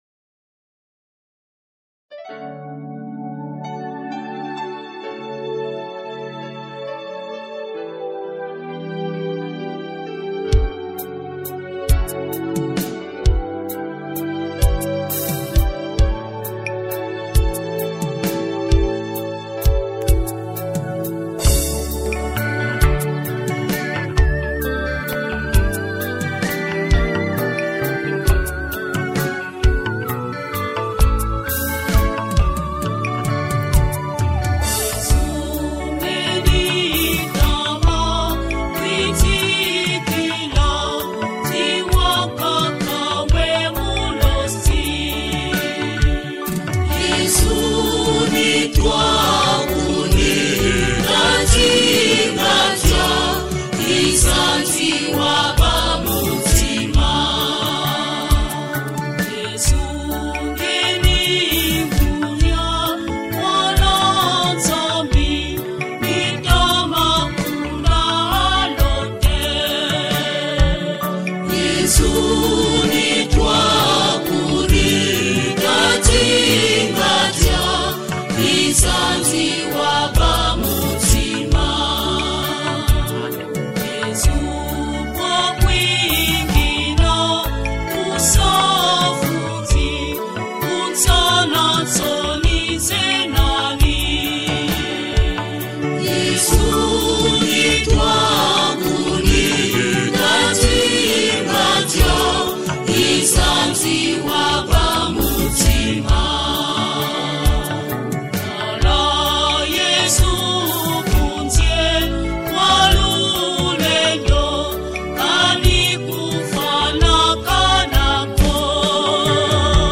Music traditionnelle | Traduction de la Bible en Téké-tyee
Chansons traditionnelle du groupe kingoli.